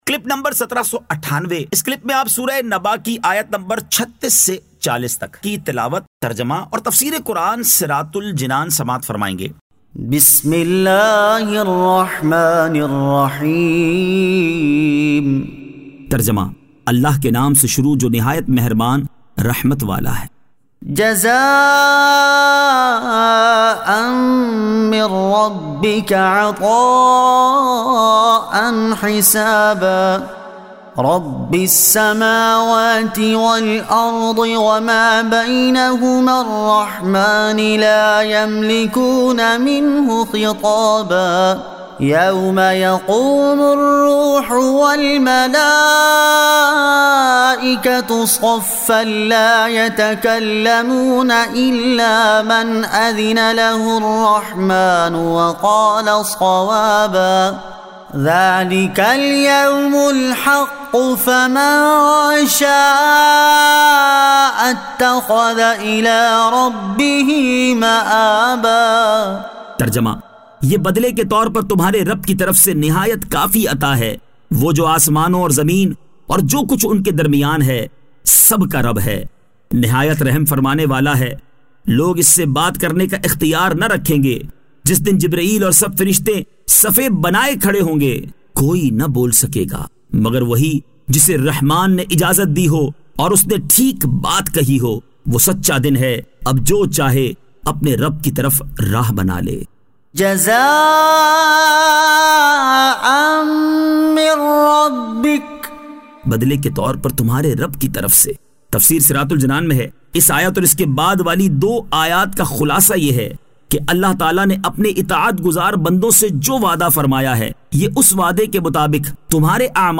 Surah An-Naba 36 To 40 Tilawat , Tarjama , Tafseer